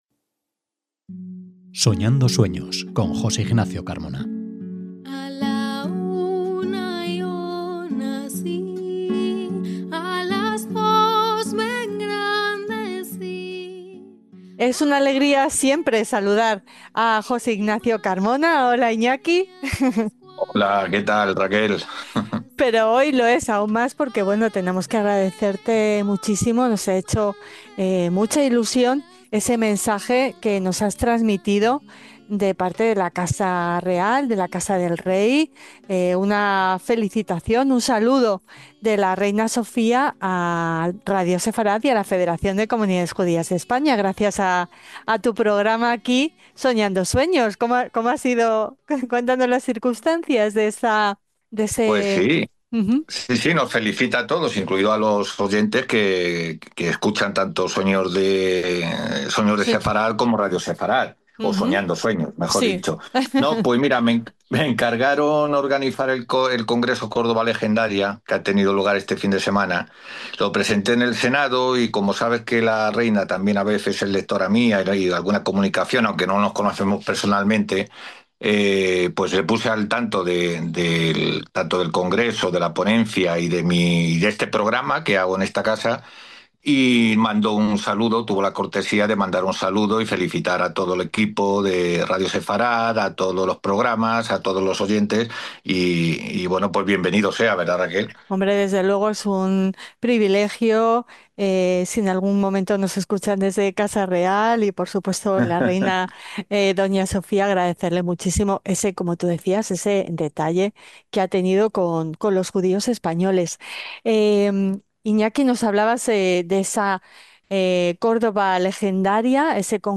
Una conversación que comienza con un saludo de la Reina Sofía y termina revelando la presencia judía en una Capilla Sixtina llena de cabalá.